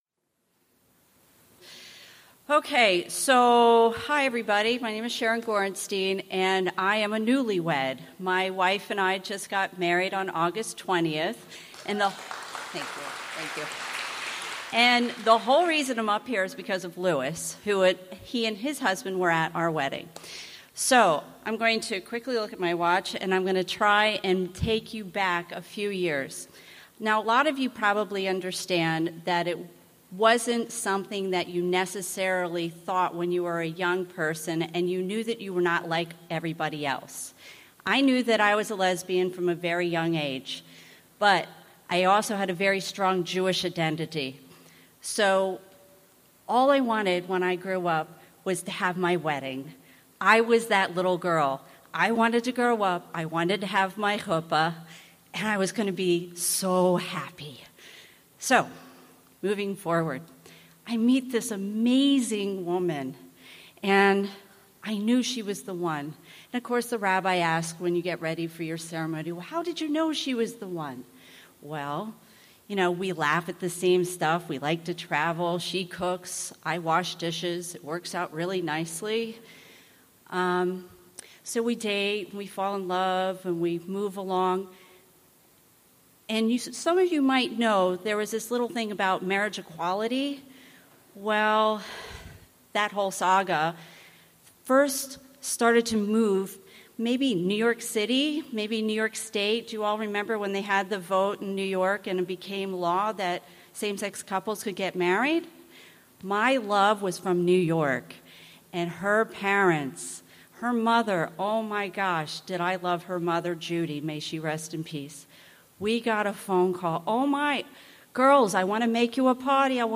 Just Married! Wedding Stories from Jewish Maryland — The Live Show! https